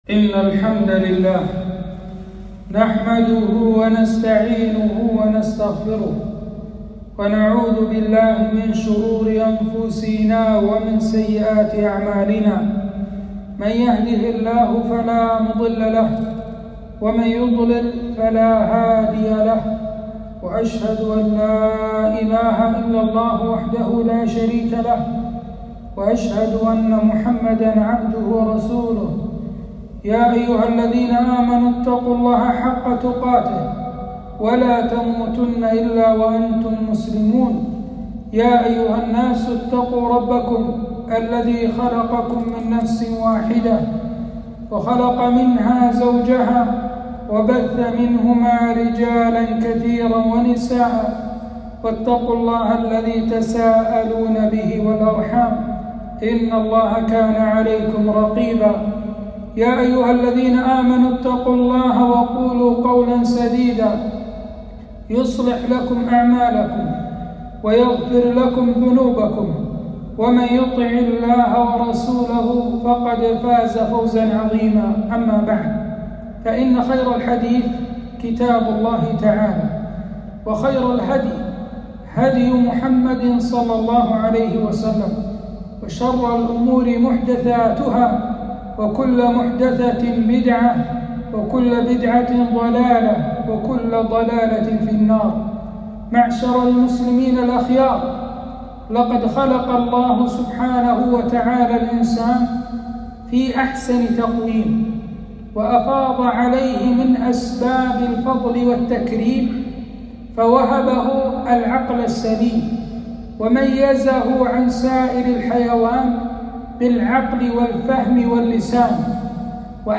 خطبة - أسباب الإدمان على المخدرات